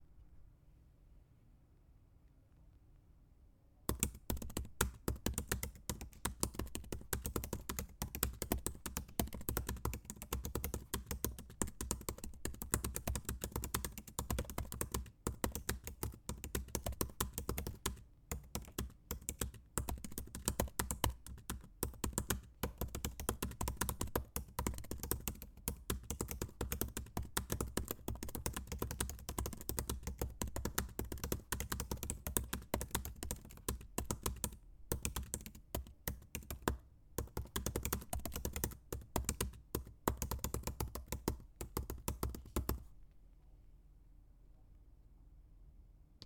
Fast typing Macbook Keyboard
clacking click clicking computer fast-typing field-recording keyboard keystroke sound effect free sound royalty free Sound Effects